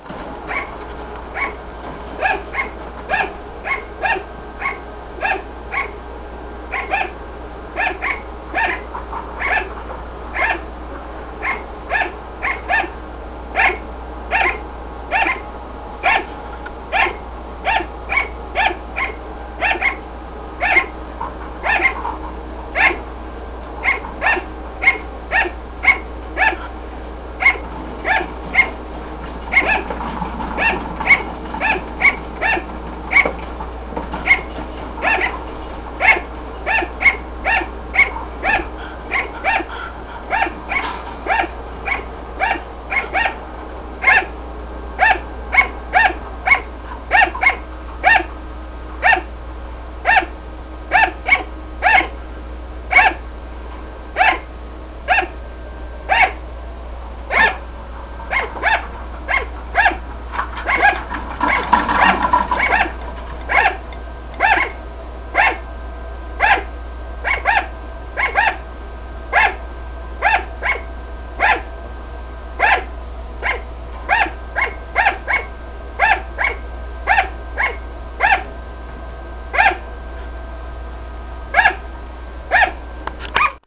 Barking is simply a loud repeated "bark" that is believed to be used to find others or to warn others.
Barking